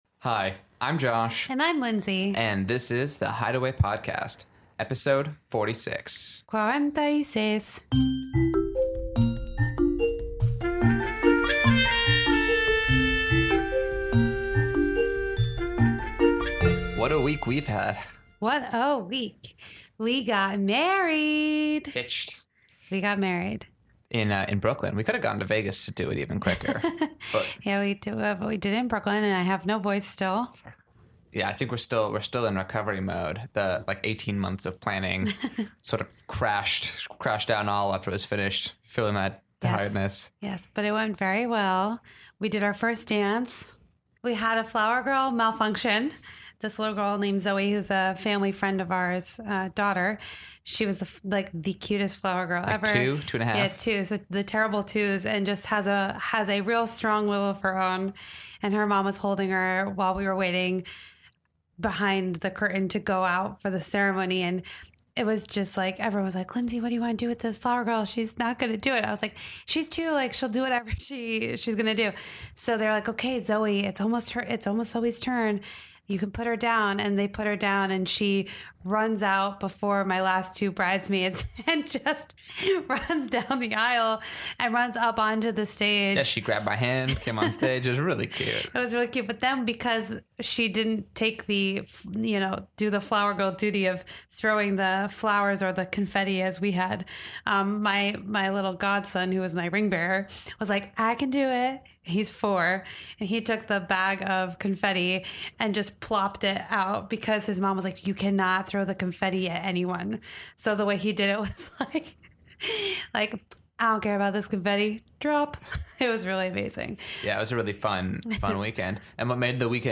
They also sit down for an interview with theater producer and entrepreneur...